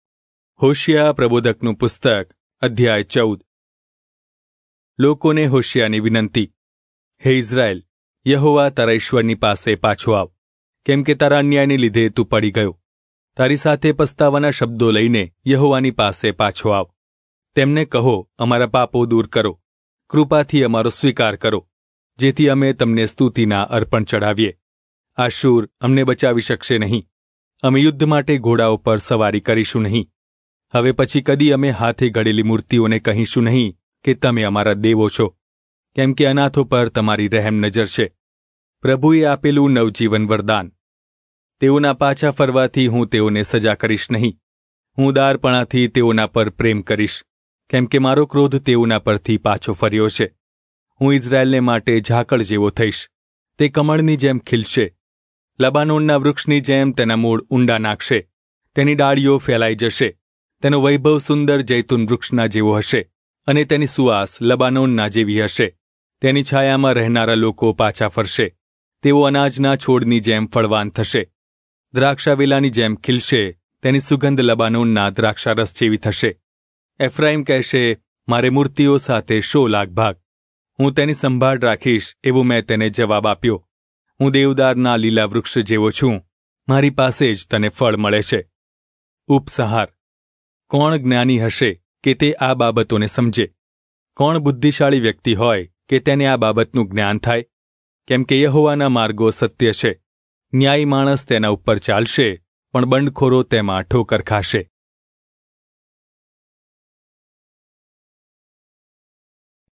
Gujarati Audio Bible - Hosea All in Irvgu bible version